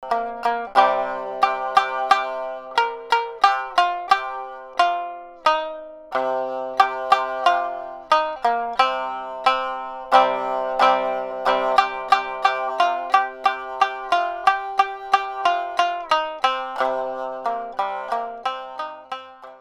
Traditional fishing folk song (Minyo) for shamisen.
• niagari tuning (C-G-C)